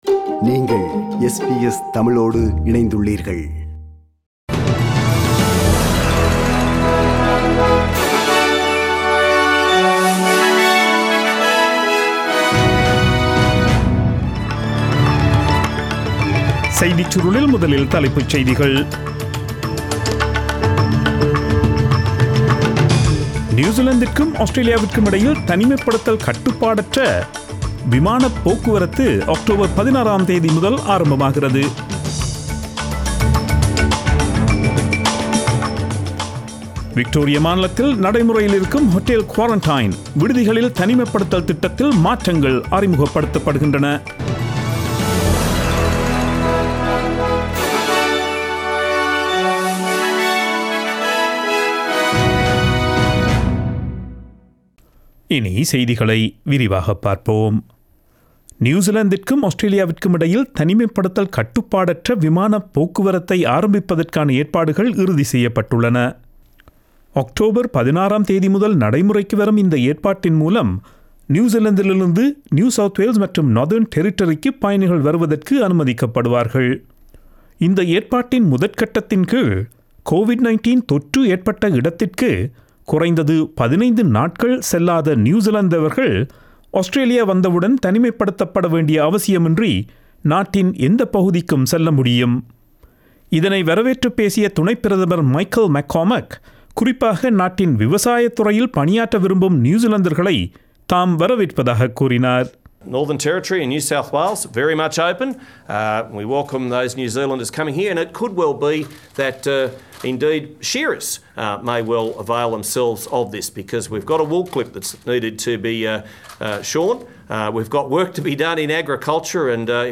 Australian news bulletin for Friday 02 October 2020.